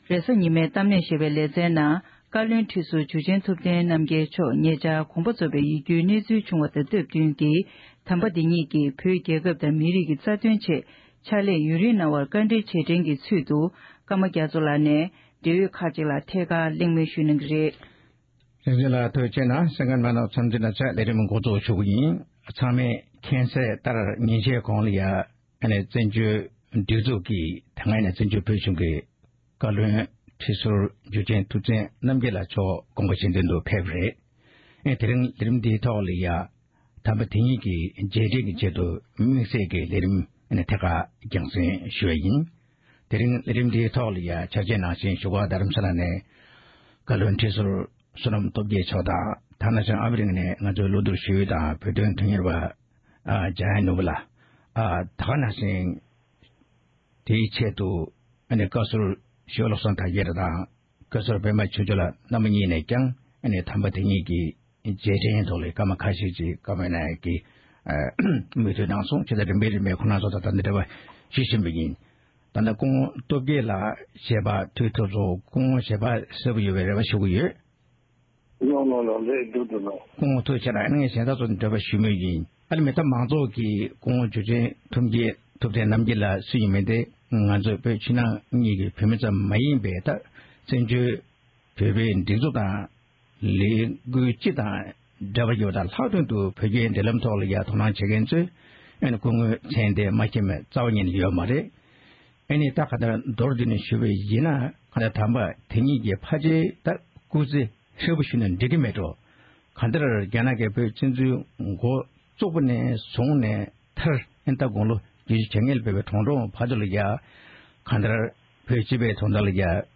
རེས་གཟའ་ཉི་མའི་གཏམ་གླེང